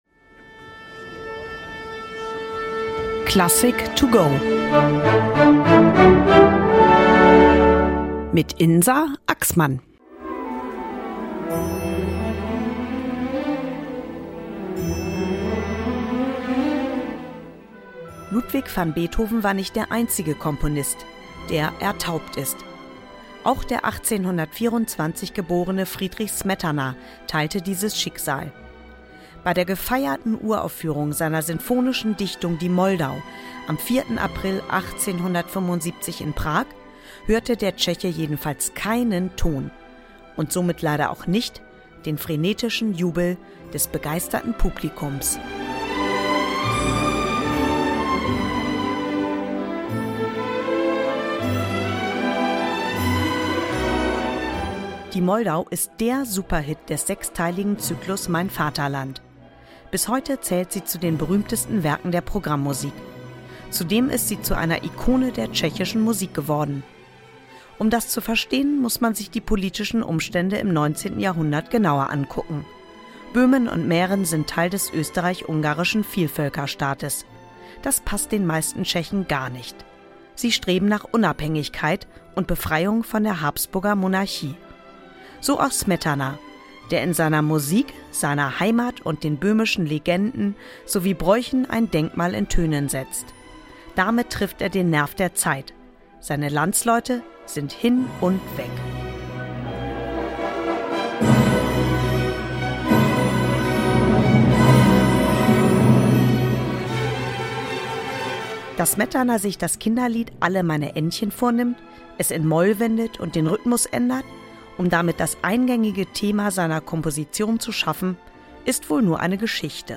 über das Werk des Komponisten in der kurzen Werkeinführung für